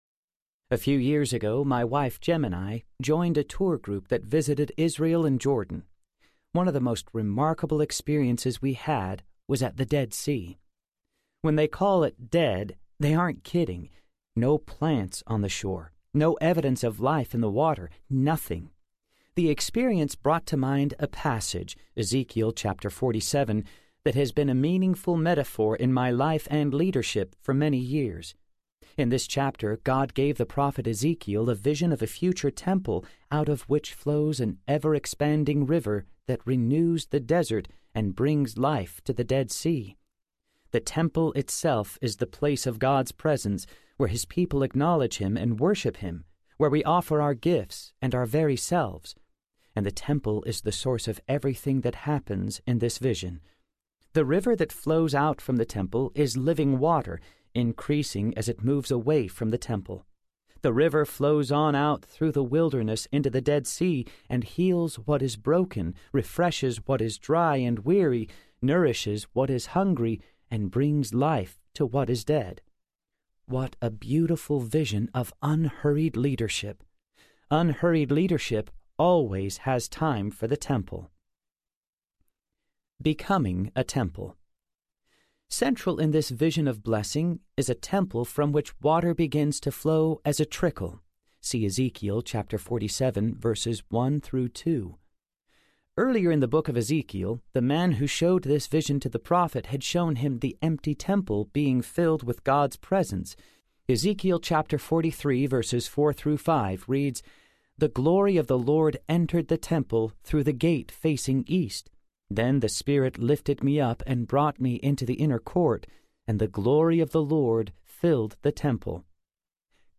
An Unhurried Leader Audiobook
Narrator
6.3 Hrs. – Unabridged